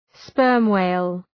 Shkrimi fonetik {‘spɜ:rm,weıl}